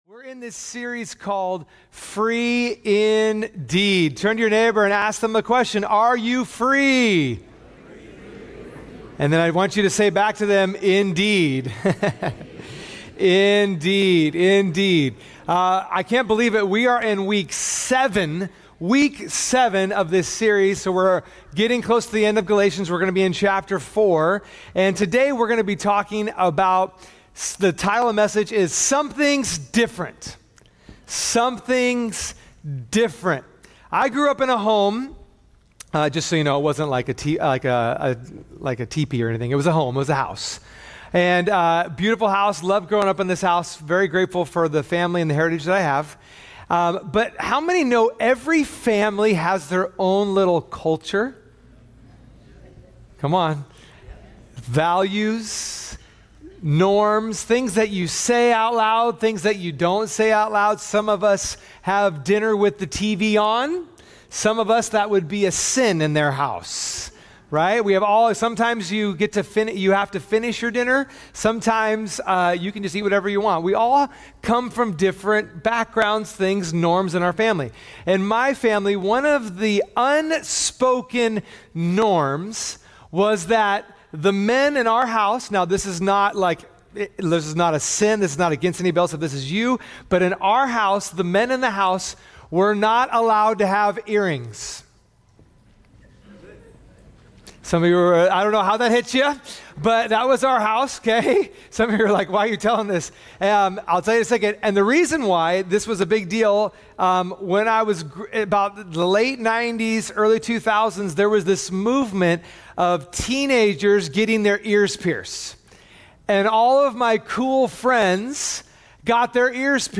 Sunday Messages